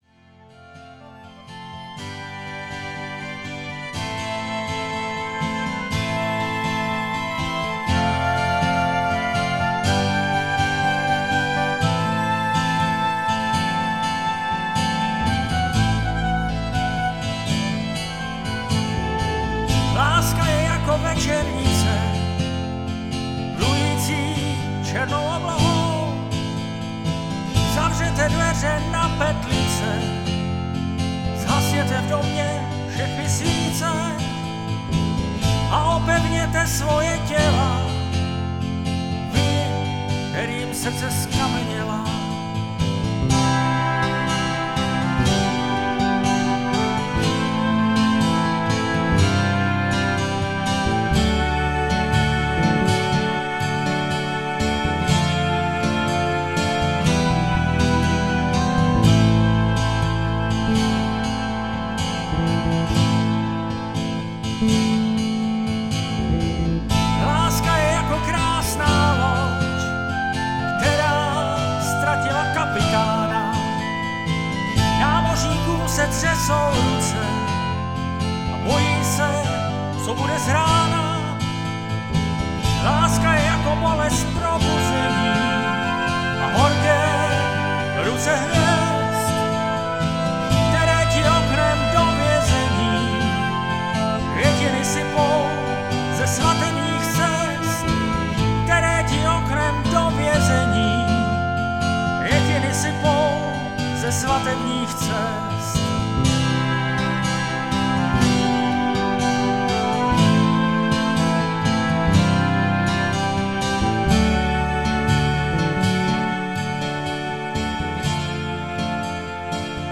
UKÁZKY SE ZPĚVÁKEM V TRIU